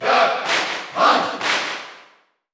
Category: Crowd cheers (SSBU) You cannot overwrite this file.
Duck_Hunt_Cheer_Dutch_SSBU.ogg.mp3